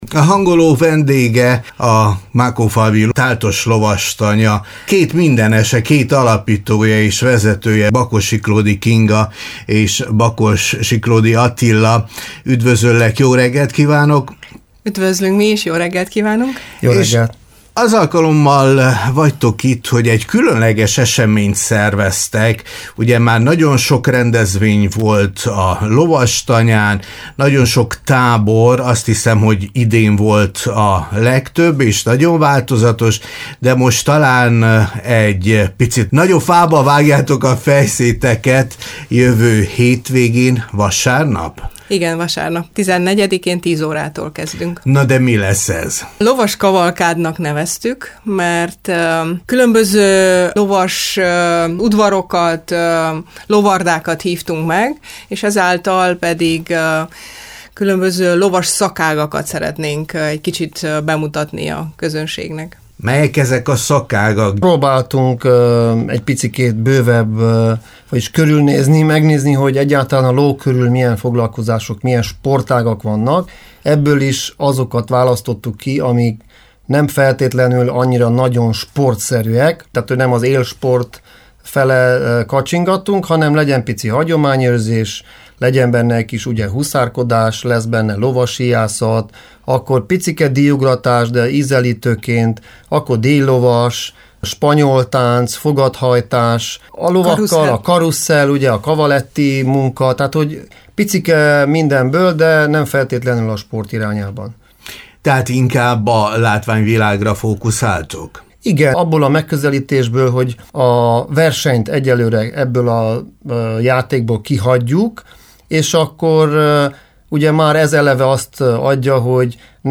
interviu-5-septembrie.mp3